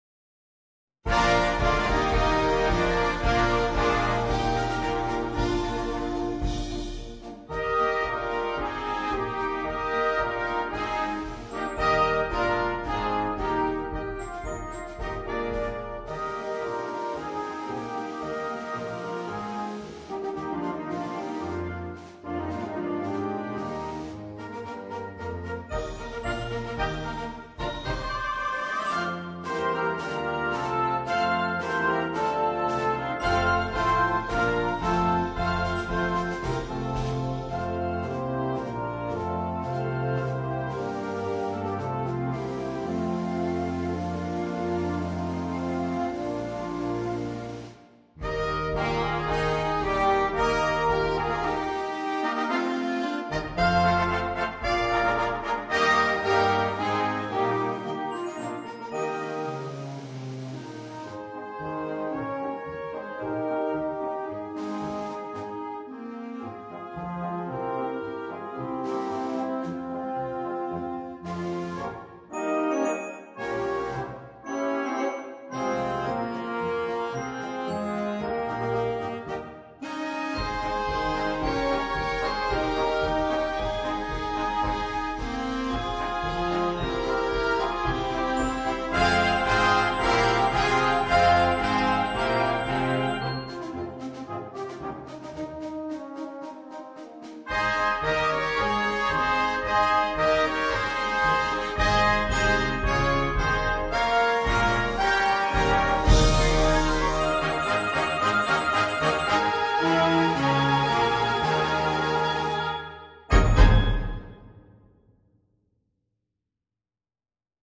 MUSICA PER BANDA